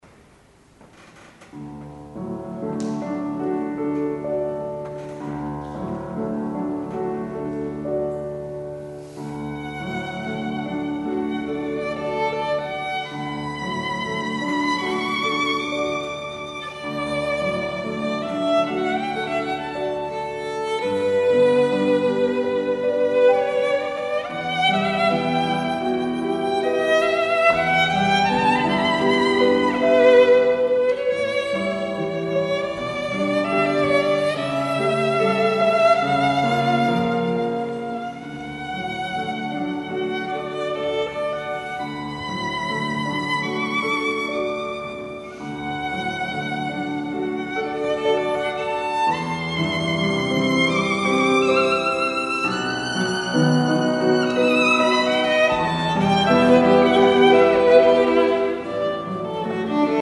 This is the live record. Sorry for some ambiance noises.